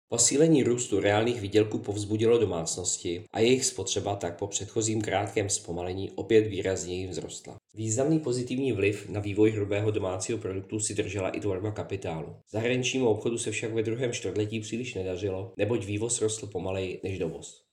Vyjádření Jaroslava Sixty, místopředsedy Českého statistického úřadu, soubor ve formátu MP3, 695.63 kB